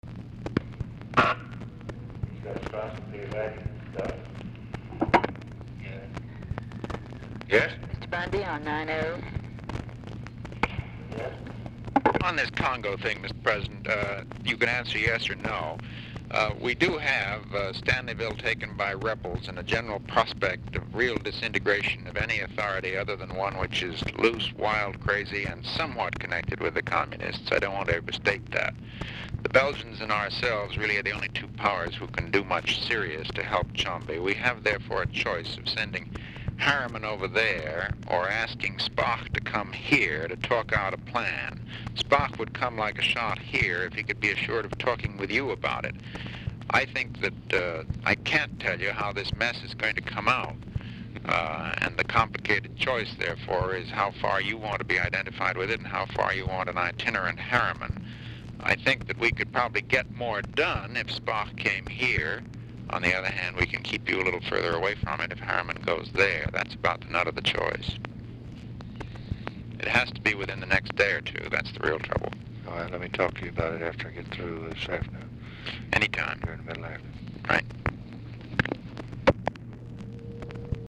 Telephone conversation # 4774, sound recording, LBJ and MCGEORGE BUNDY, 8/6/1964, 1:05PM | Discover LBJ
Format Dictation belt
Location Of Speaker 1 Oval Office or unknown location
Specific Item Type Telephone conversation